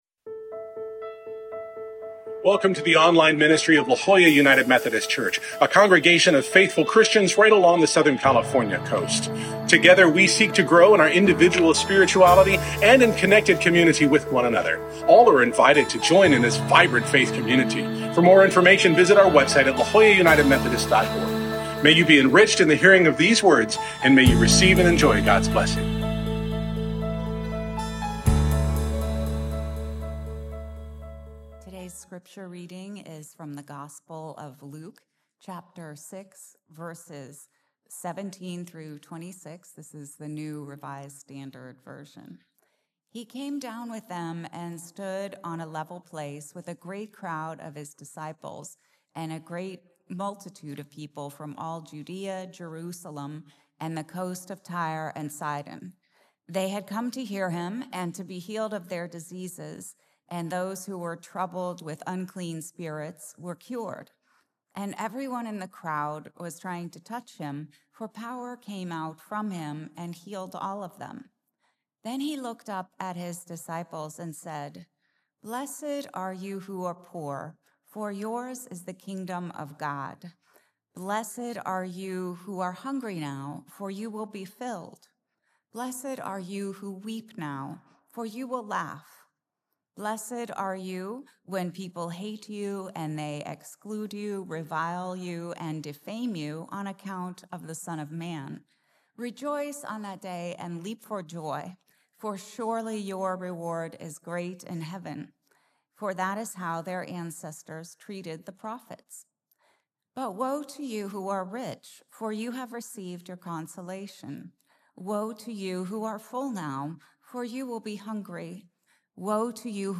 In Luke 6, Jesus challenges our assumptions about blessings and woes, calling us to see the world through God’s vision. Join us for worship as we continue our sermon series, Foundations & Futures, exploring how God’s presence shapes us now and calls us into the future.